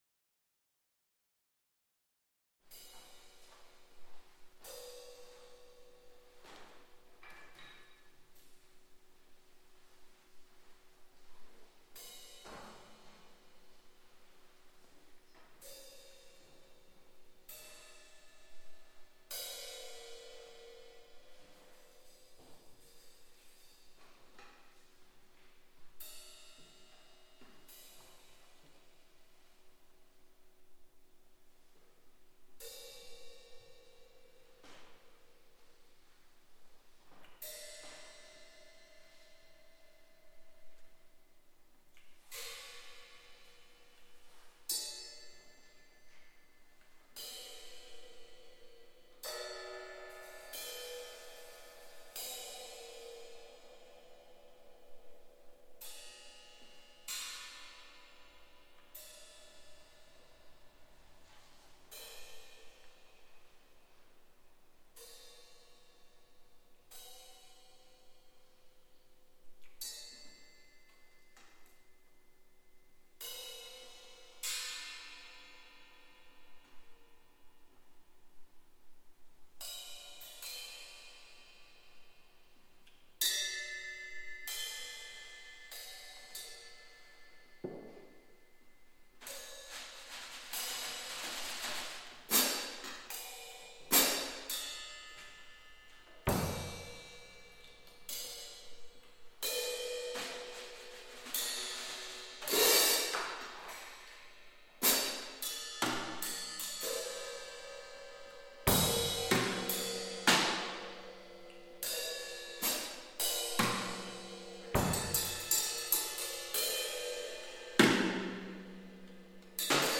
Sound recording of 'Piece for Drum Kit'